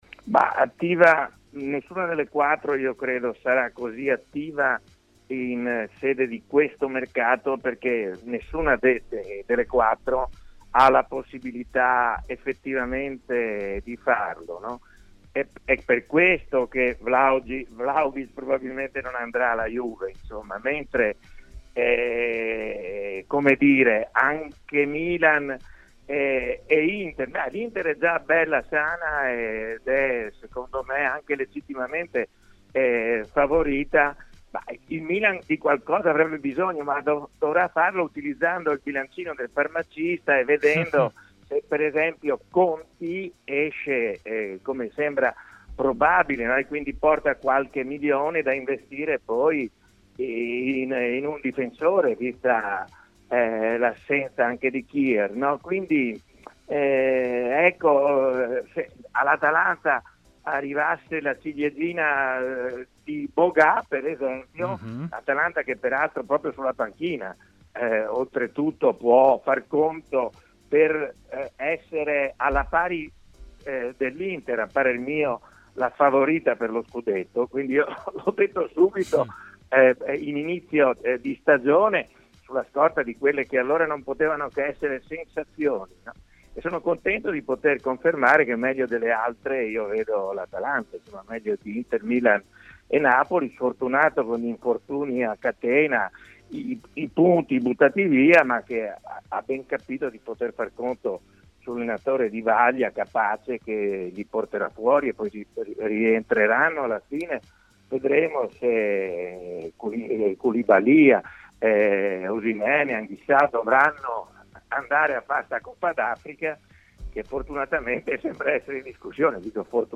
trasmissione pomeridiana di TMW Radio